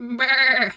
sheep_2_baa_calm_02.wav